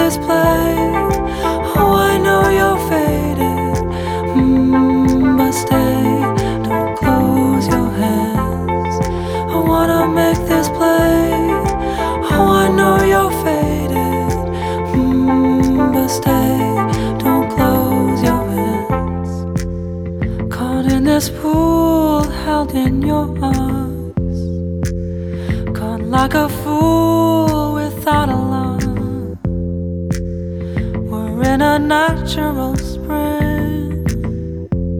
Жанр: Электроника / Рок / Альтернатива